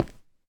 stone2.ogg